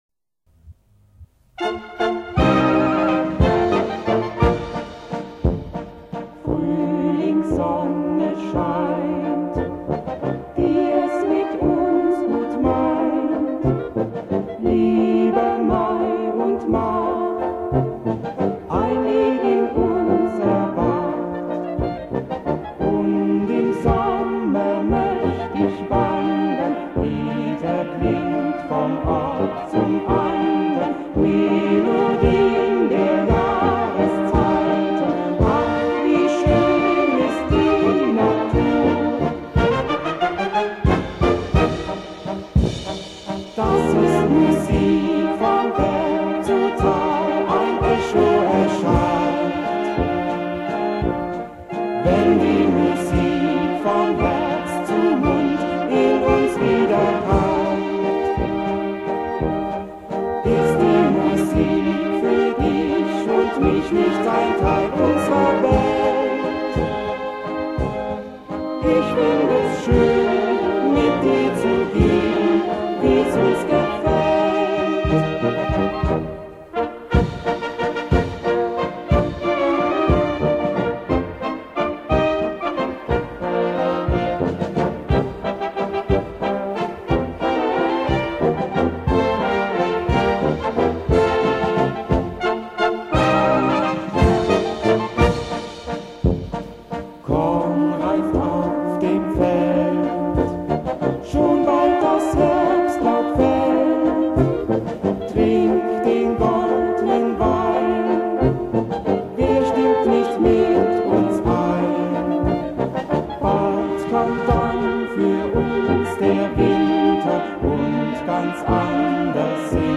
Walzer mit Gesang (Duett Alt/Tenor) Komposition: Folklore Arrangement: Wilfried Walther Text: Ulrich Berger Hörbeispiel Orchesterversion Hörbeispiel mit Gesang Noten zum kostenfreien download: Gesang 1.